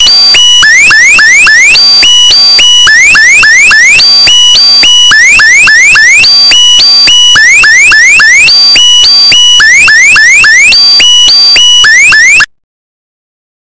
loud sound